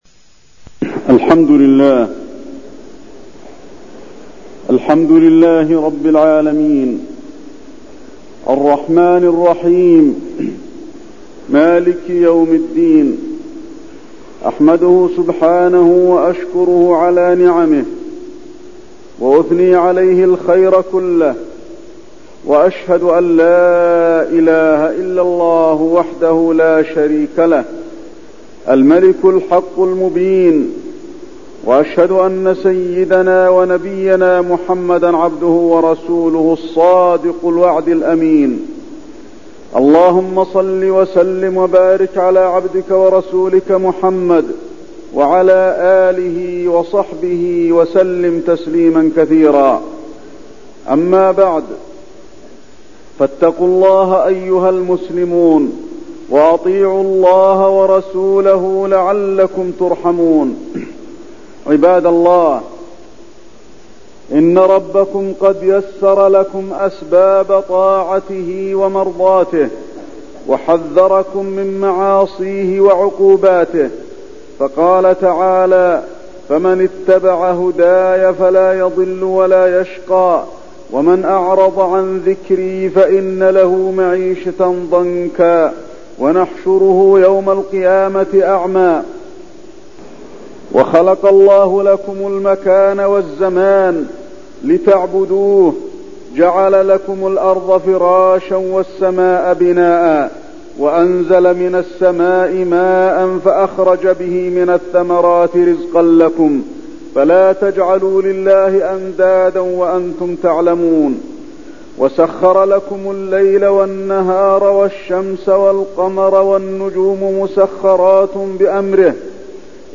خطبة التدبر في آيات الليل والنهار وفيها: تهيئة الكون ليناسب عبادة الله، وتعاقب الليل والنهار، وخطورة الغفلة في تعاقب الليل والنهار
تاريخ النشر ٨ محرم ١٤٠٧ المكان: المسجد النبوي الشيخ: فضيلة الشيخ د. علي بن عبدالرحمن الحذيفي فضيلة الشيخ د. علي بن عبدالرحمن الحذيفي التدبر في آيات الليل والنهار